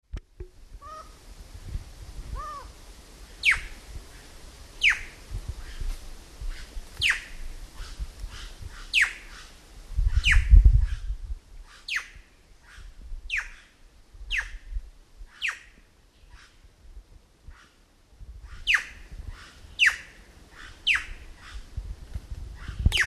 Plush-crested Jay (Cyanocorax chrysops)
Aparentemente algunas imitaban al Micrastur semitorquatus. de ahi lo llamativo de la grabación
Location or protected area: Parque Provincial Puerto Península
Condition: Wild
Certainty: Observed, Recorded vocal